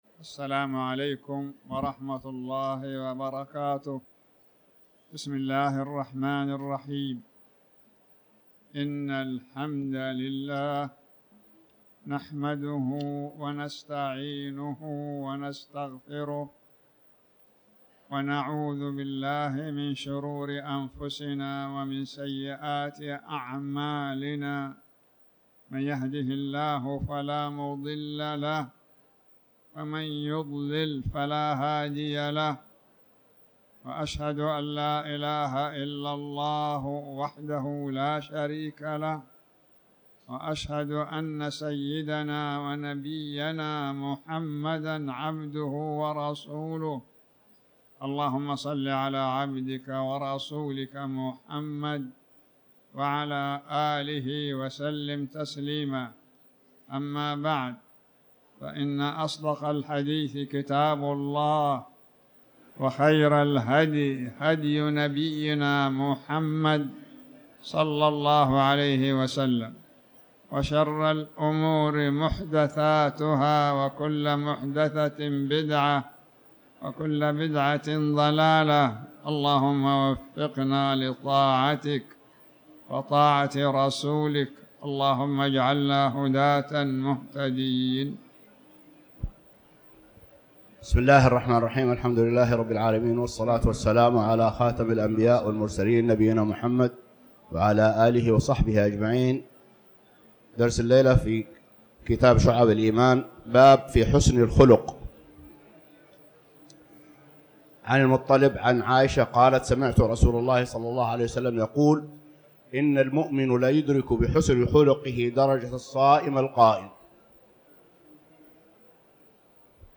تاريخ النشر ٢٤ رجب ١٤٤٠ هـ المكان: المسجد الحرام الشيخ